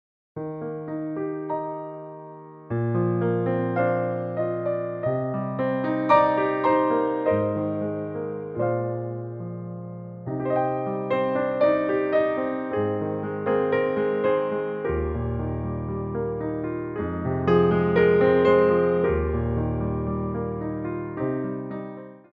Ports de Bras / Révérance
4/4 (8x8) + (2x8)